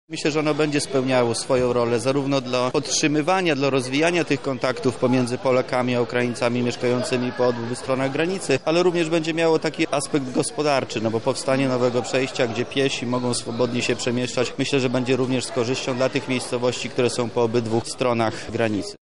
Uruchamiamy dziś przejście pieszo-rowerowe, umożliwi ono nieskrępowany ruch wszystkim obywatelom – mówi Wojciech Wilk, wojewoda lubelski.